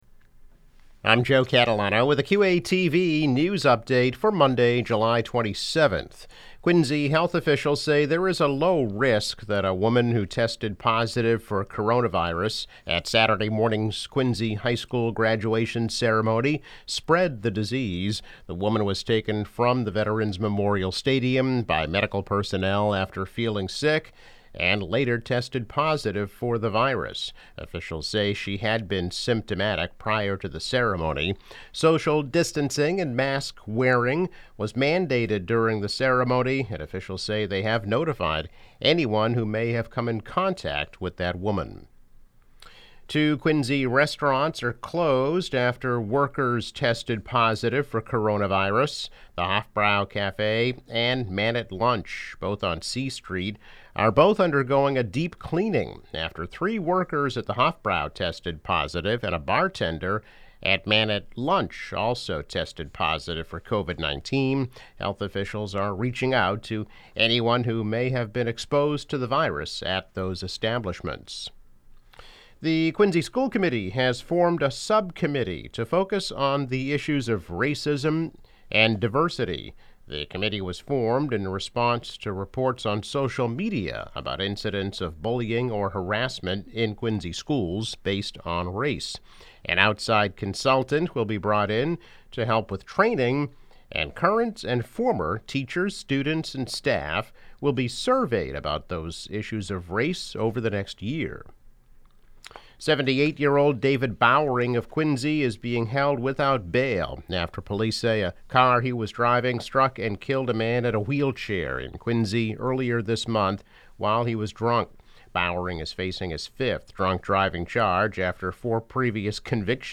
News Update - July 27, 2020